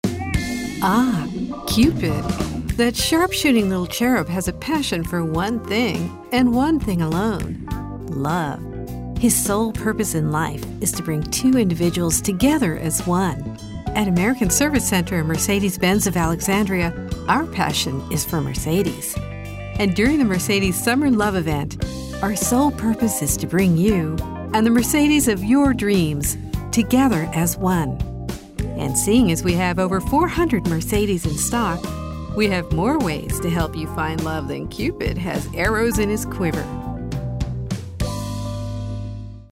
a smooth and resonant voice
Car ad: (light, sexy, humor)